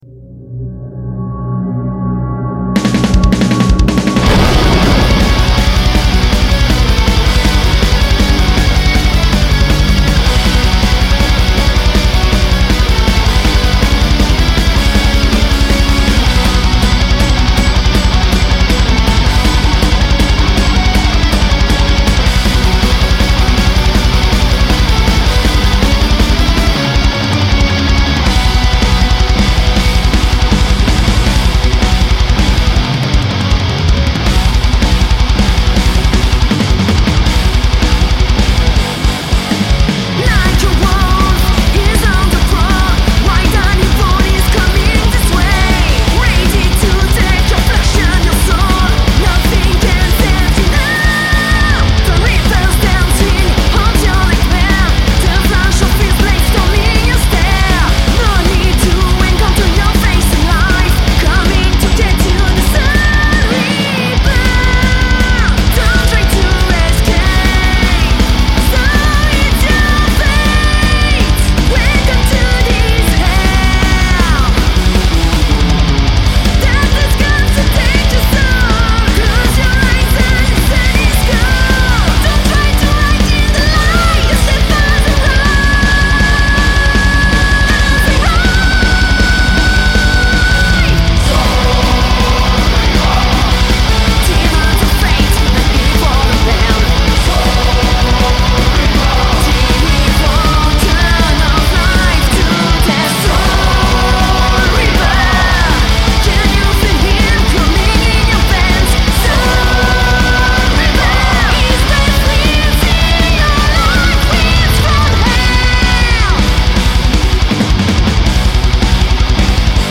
heavy metal France